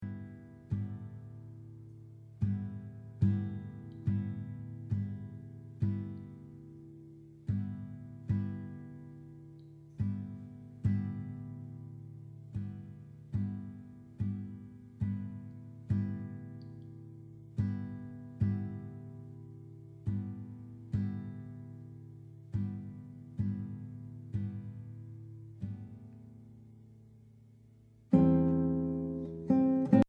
Obras para guitarra